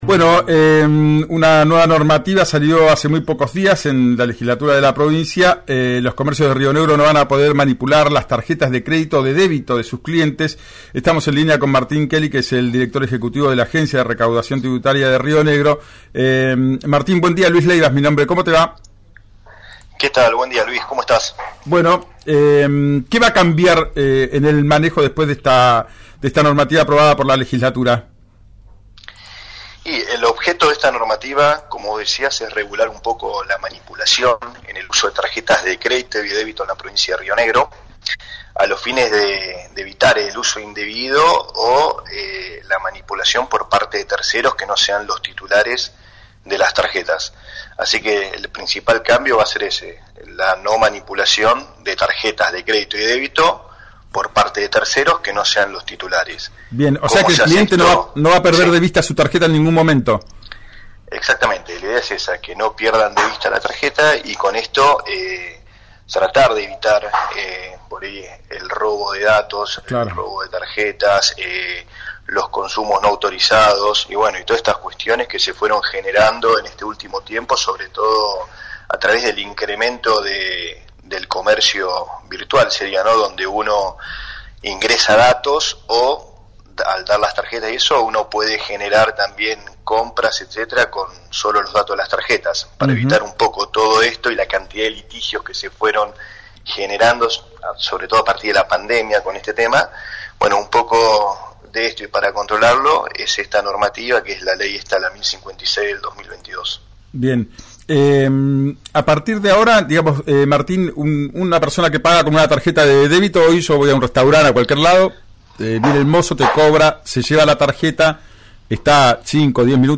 Escuchá a Martín Kelly, director ejecutivo de la Agencia de Recaudación Tributaria de Río Negro, en RÍO NEGRO RADIO: